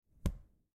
Закрыли крышку ноутбука и что случилось